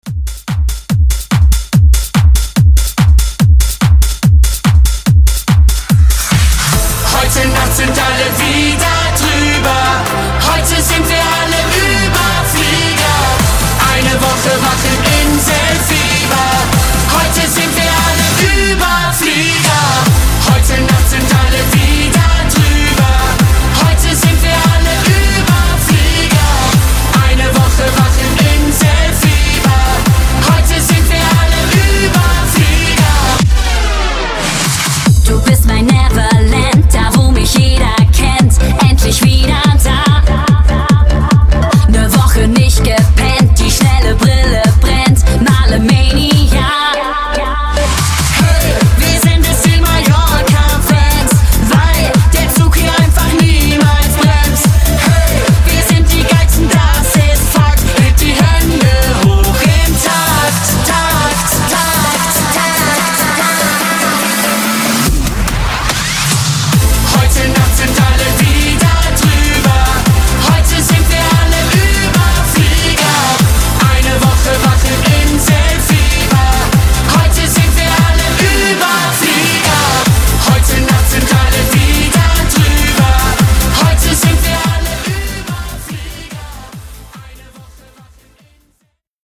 Genres: EDM , GERMAN MUSIC , RE-DRUM
Dirty BPM: 150 Time